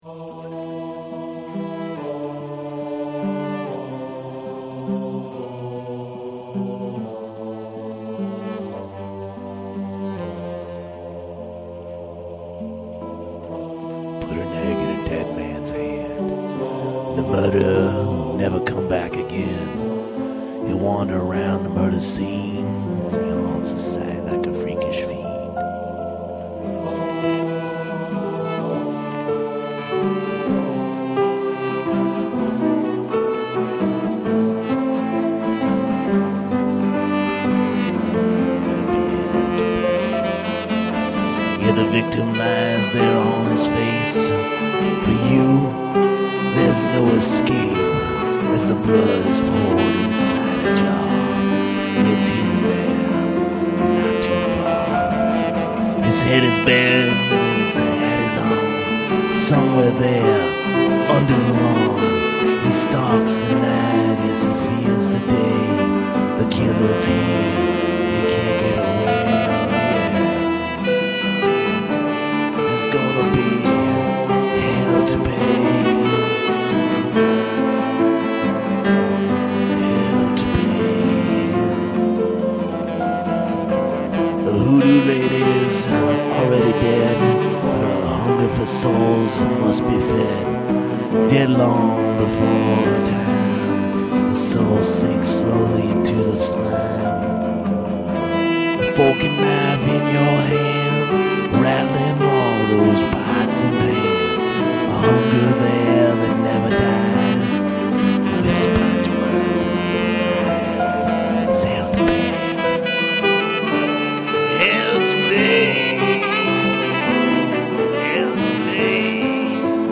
Guitar & Vocals
Keyboards
Percussion
Tenor saxophone